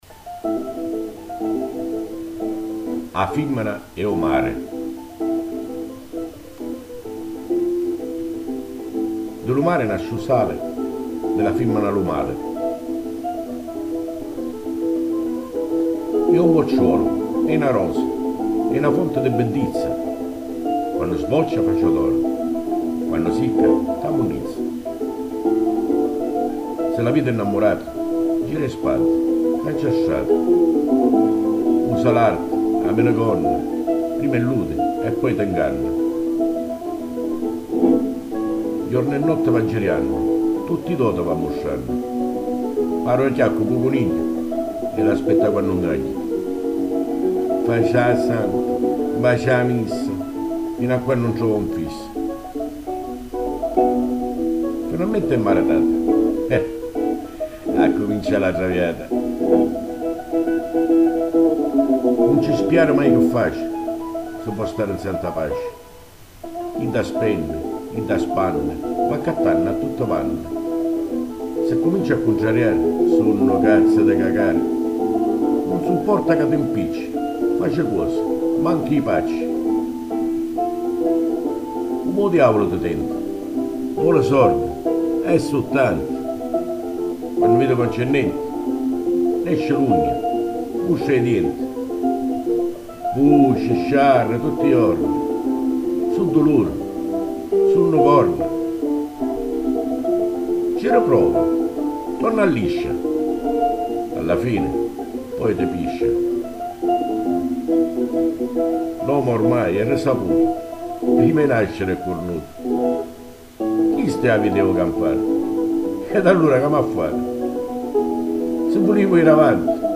nel suo home studio recorder, poesia recitata.